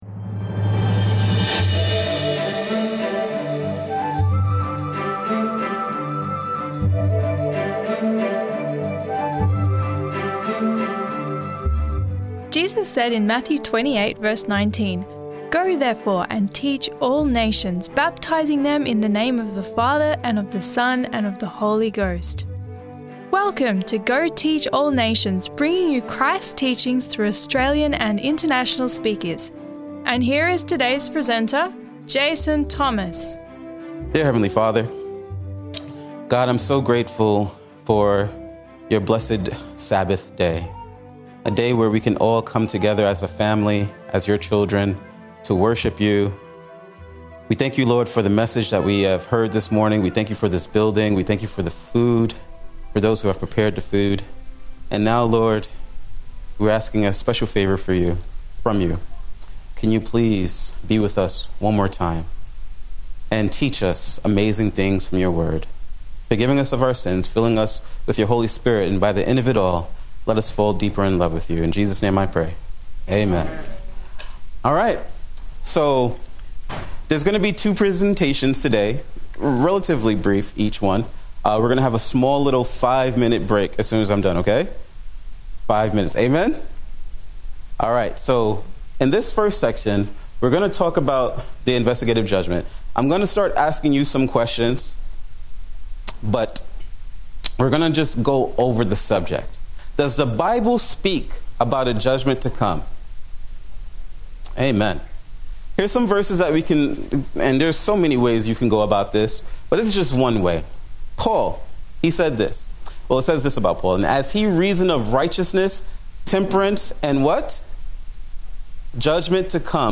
It is the worldwide proclamation of the everlasting gospel that ushers in the return of Christ. We are pleased to bring you Christ’s teachings through sermon of Australian and international speakers.